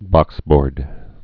(bŏksbôrd)